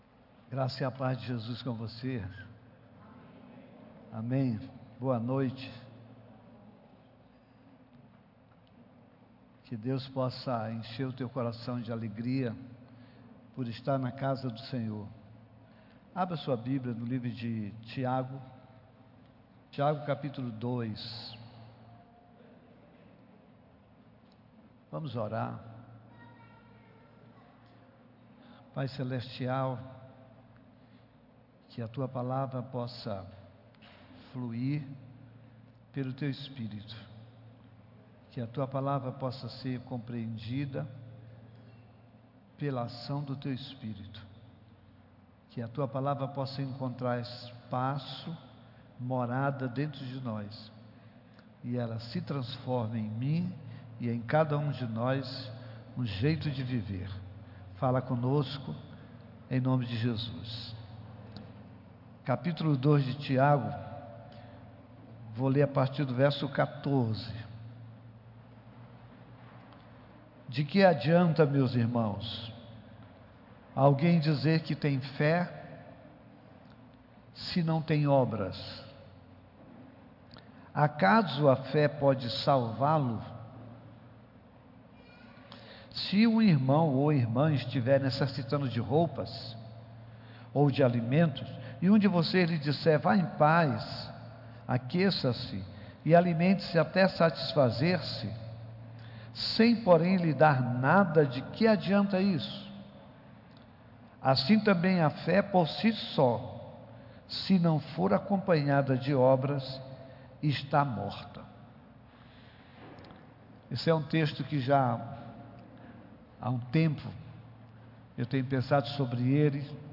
Culto presencial aos domingos às 18 h.